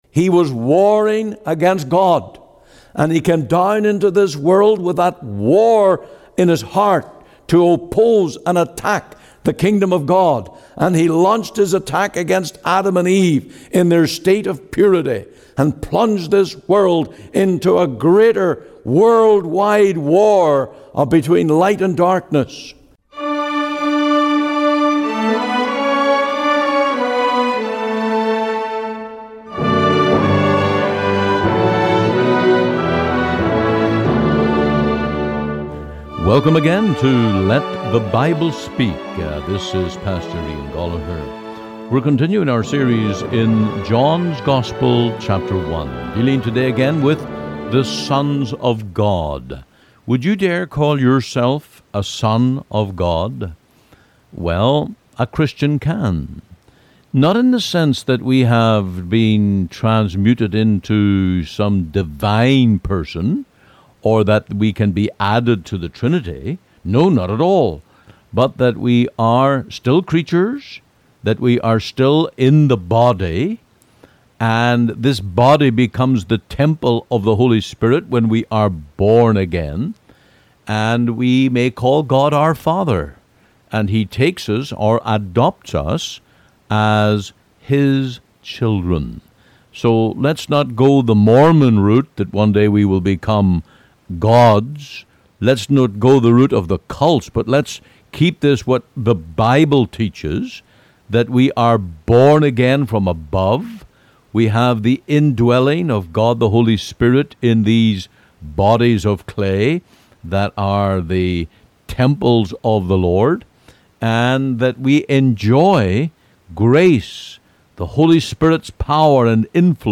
Sermons | The Free Presbyterian Church in Cloverdale